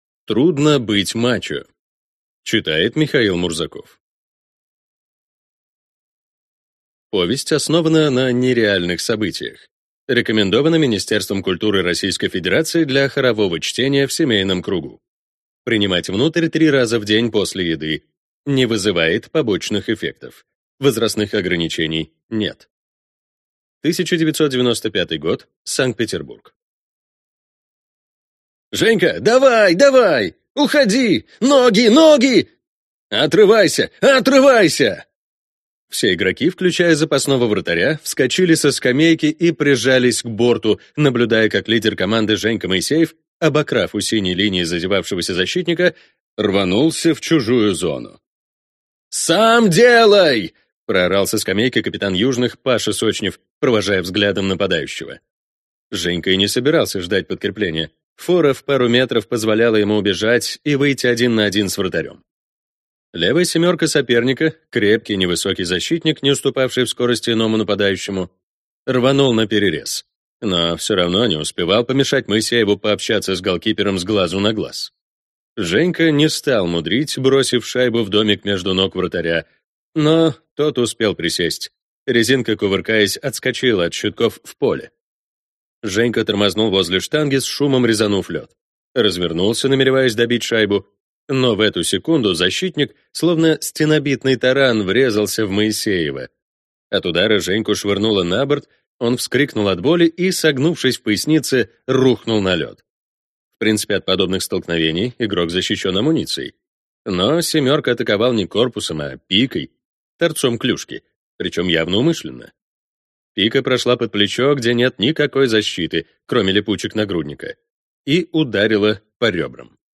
Аудиокнига Трудно быть мачо | Библиотека аудиокниг